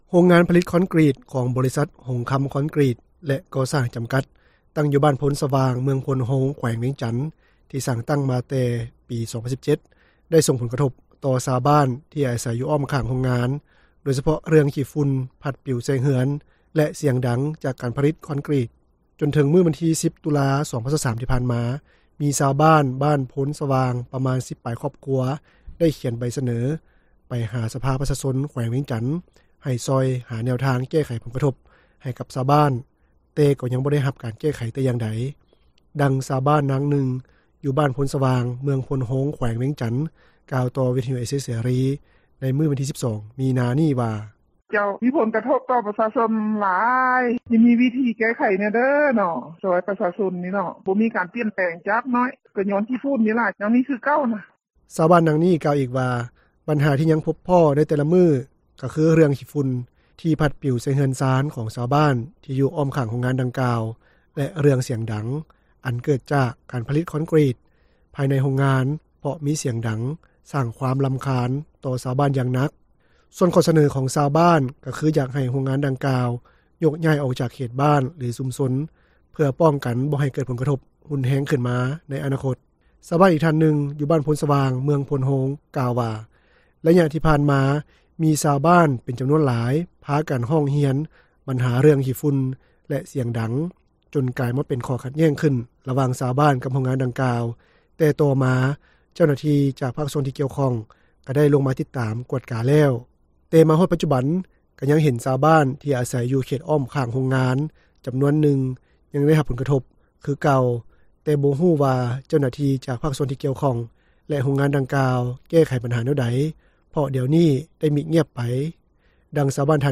ດັ່ງຊາວບ້ານ ນາງນຶ່ງ ຢູ່ບ້ານໂພນສະຫວ່າງ, ເມືອງໂພນໂຮງ ແຂວງວຽງຈັນ ກ່າວຕໍ່ວິທຍຸເອເຊັຽເສຣີ ໃນມື້ວັນທີ 12 ມີນາ ນີ້ວ່າ:
ດັ່ງຊາວບ້ານ ທ່ານນີ້ ກ່າວຕໍ່ວິທຍຸເອເຊັຽເສຣີ ໃນມື້ດຽວກັນນີ້ວ່າ:
ດັ່ງເຈົ້າໜ້າທີ່ ນາງນີ້ ກ່າວຕໍ່ວິທຍຸເອເຊັຽເສຣີ ໃນມື້ດຽວກັນນີ້ວ່າ:
ດັ່ງຕາງໜ້າບໍຣິສັດ ທ່ານນີ້ ກ່າວຕໍ່ວິທຍຸເອເຊັຽເສຣີ ໃນມື້ດຽວກັນນີ້ວ່າ: